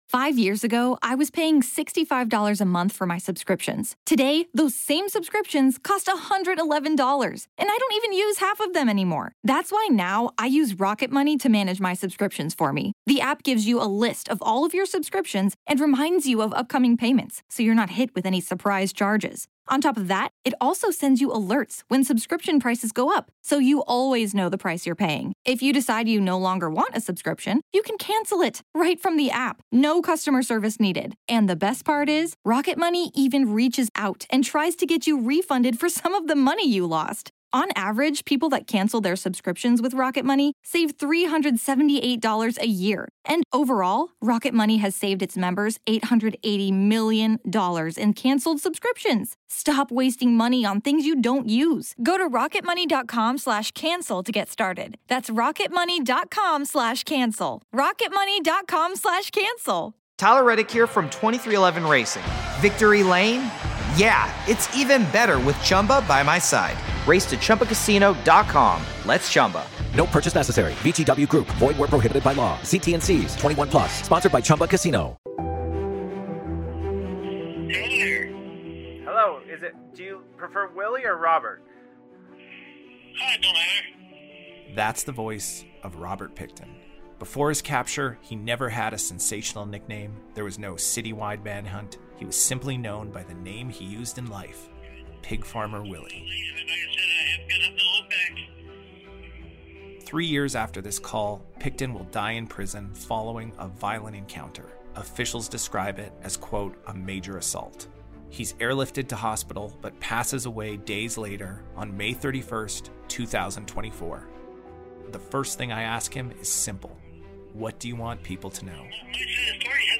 Before his death, Robert Pickton calls from prison. The recording he leaves behind — never before heard — reveals a man who still believes he hasn’t been caught.